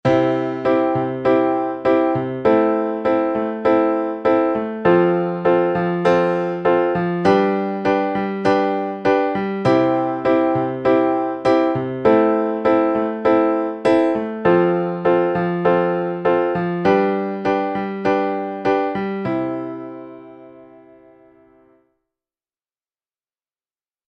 I – vi – IV – V